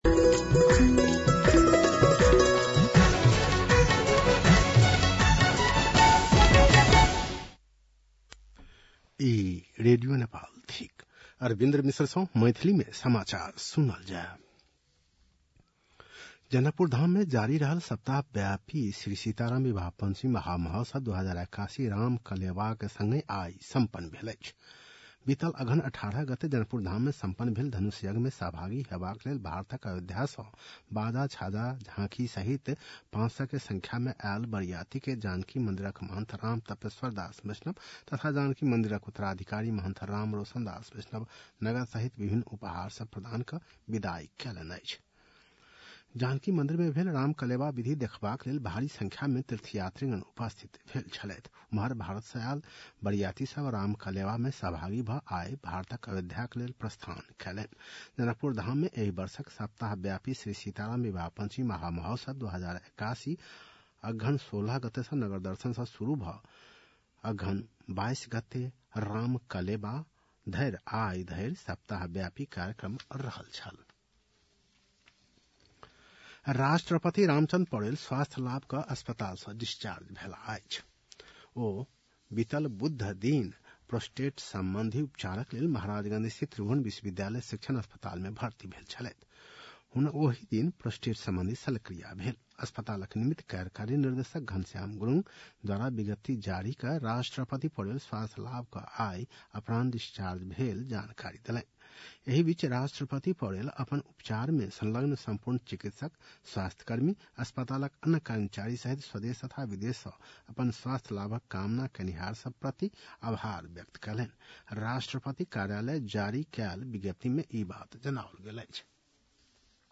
मैथिली भाषामा समाचार : २३ मंसिर , २०८१
Maithali-News-8-22.mp3